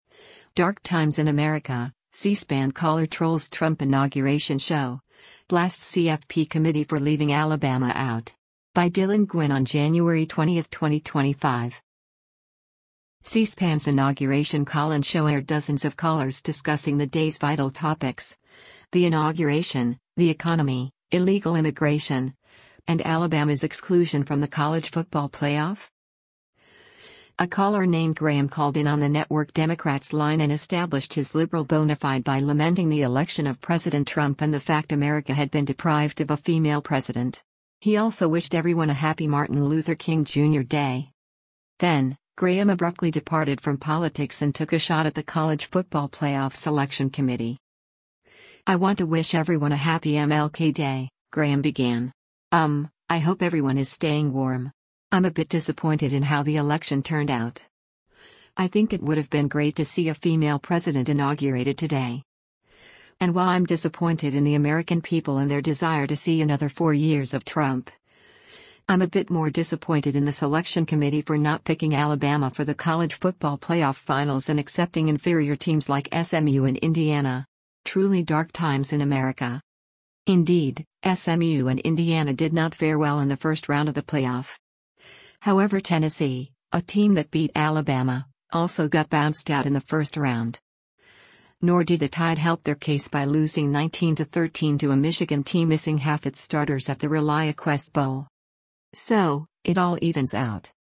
C-SPAN’s Inauguration call-in show aired dozens of callers discussing the day’s vital topics: the inauguration, the economy, illegal immigration, and Alabama’s exclusion from the College Football Playoff?